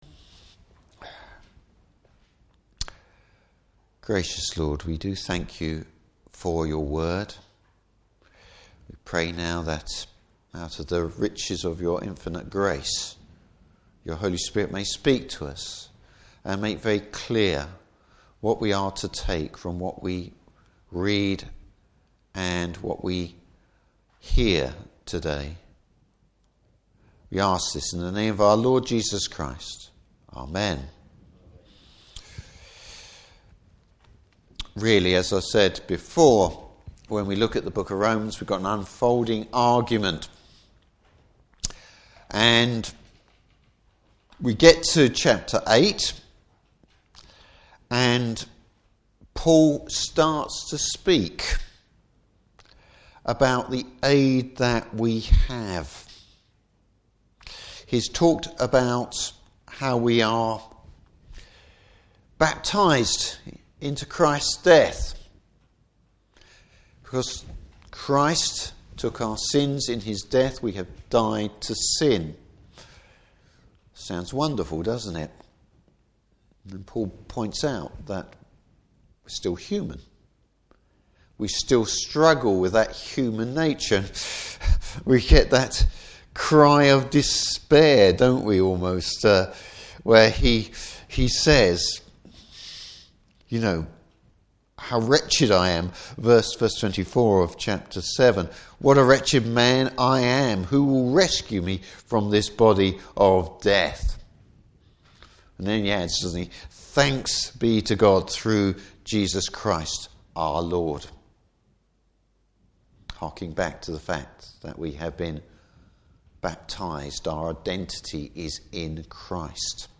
Service Type: Morning Service The recreation of the human heart by Christ.